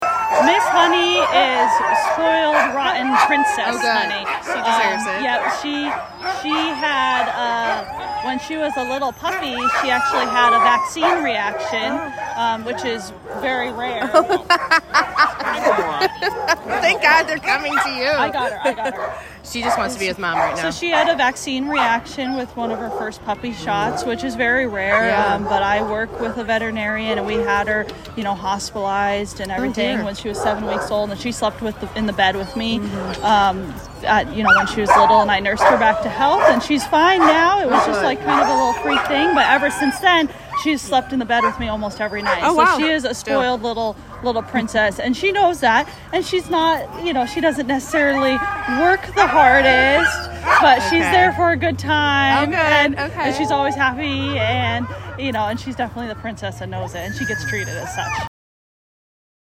Current Location: Downtown Anchorage at the Ceremonial Iditarod Start